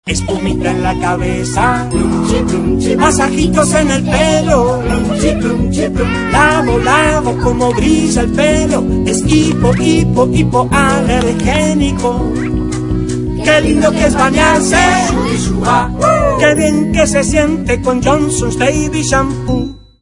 Siempre los últimos TONO DE ANUNCIOS